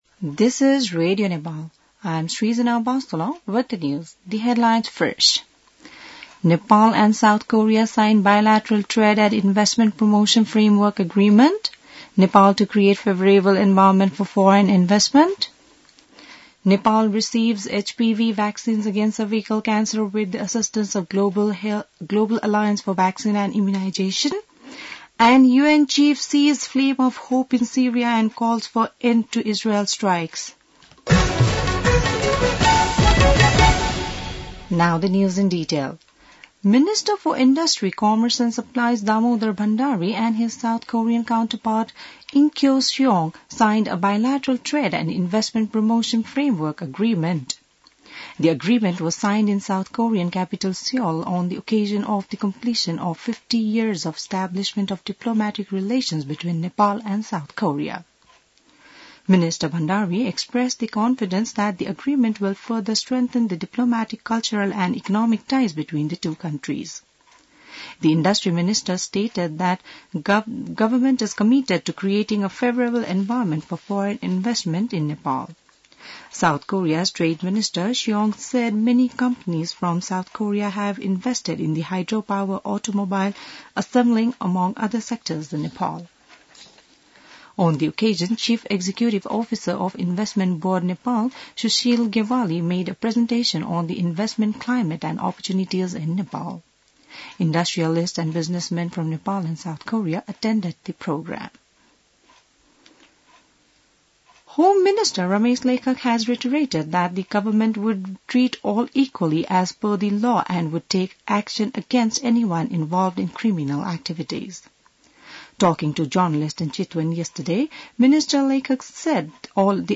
बिहान ८ बजेको अङ्ग्रेजी समाचार : ६ पुष , २०८१